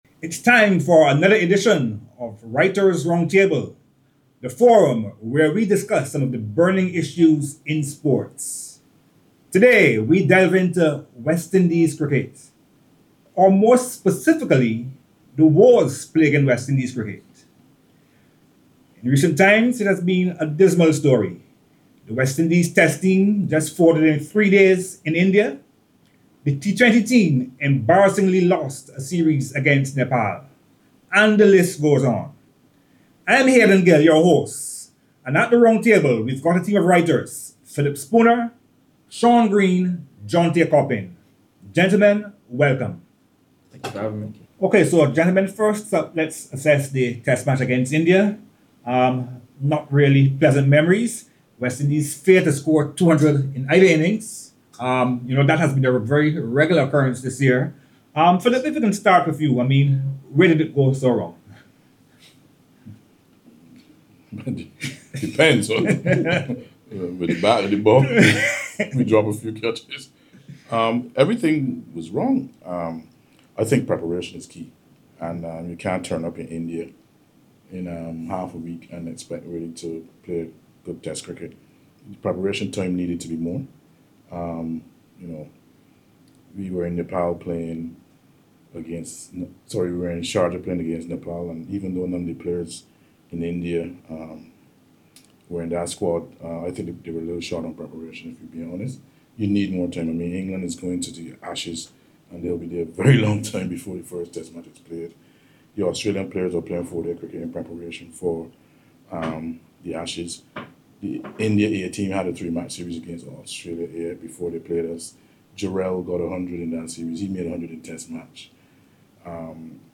In this episode of Writer's Roundtable our team of journalists discuss their views on T20 West Indies Cricket.